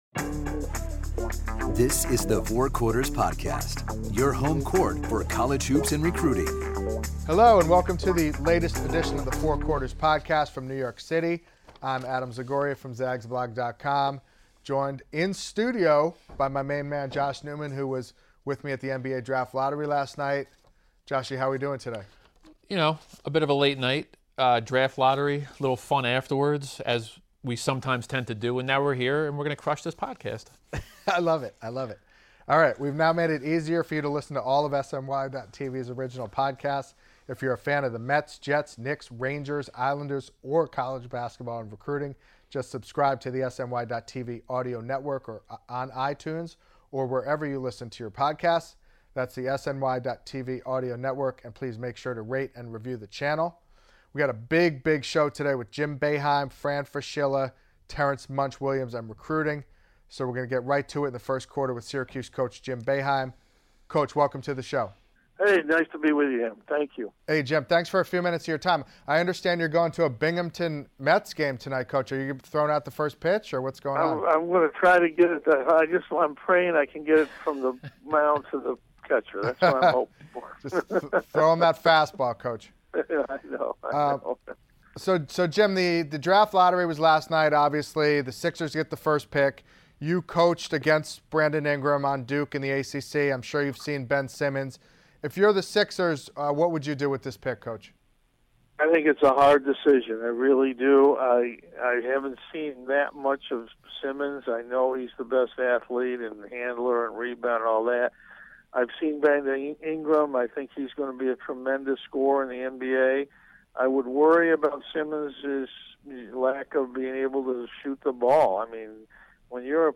First up, Syracuse head coach Jim Boeheim calls in to talk about the NBA Draft, Malachi Richardson, Tyus Battle, next year’s Orange squad, and his latest thoughts on Carmelo Anthony and the Knicks. Then, former Manhattan and St. John’s coach and current ESPN Analyst Fran Fraschilla returns to the show to talk about the NBA Draft Lottery results, and who is coming and going in the draft.